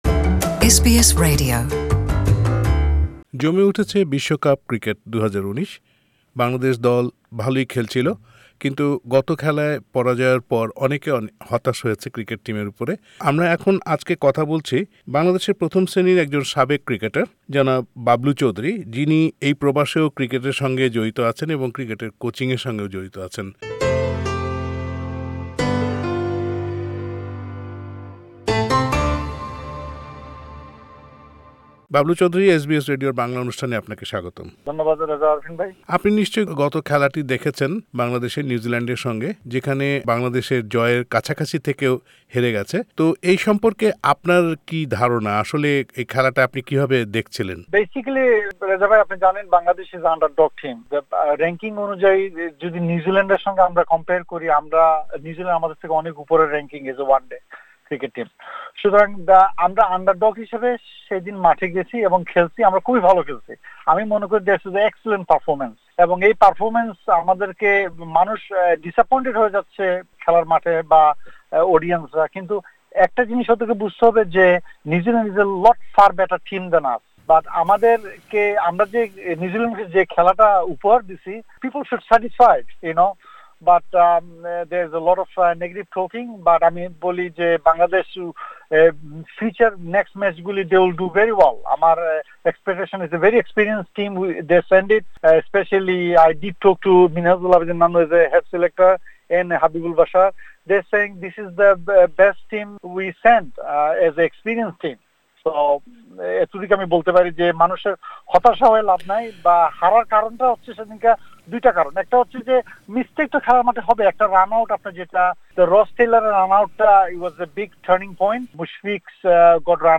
ICC Cricket World Cup 2019: Bangladesh-New Zealand match review